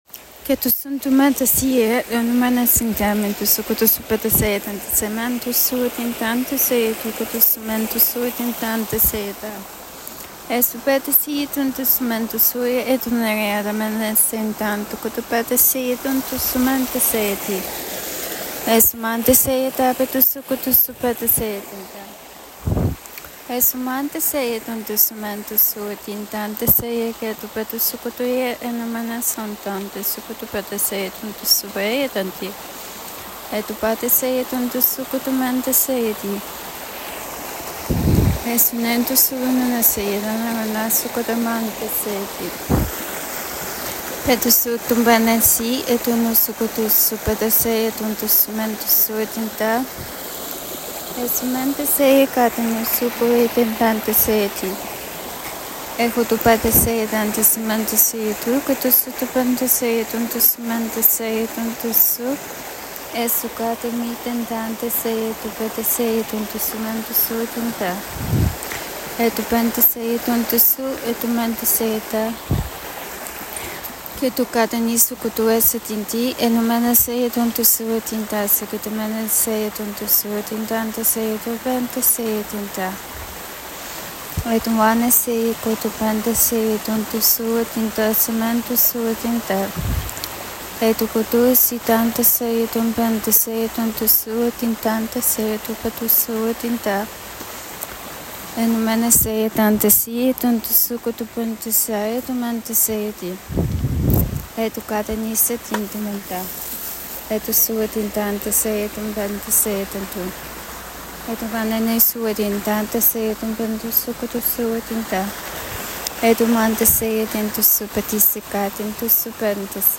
tongues-1.m4a